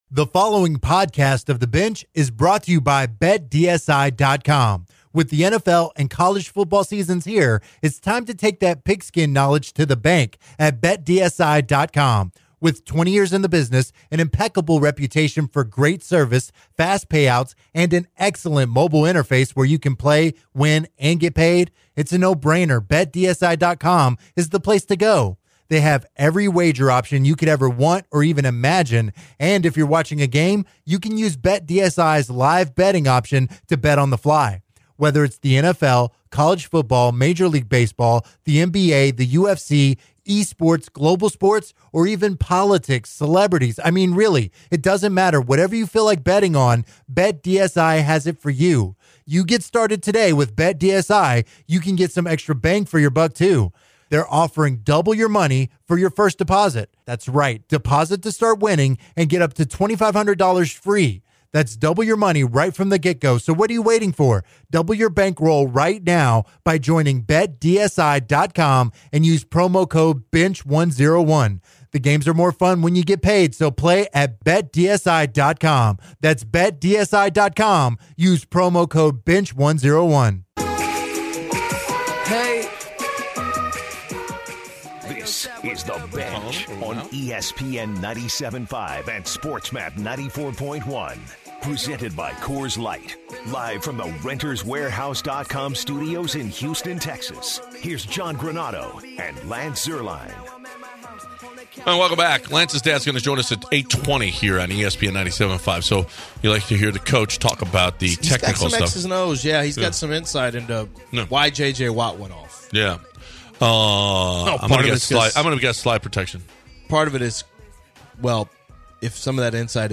To start the second hour of the show, the guys take some calls as they talk about the myriad of issues the Texans have.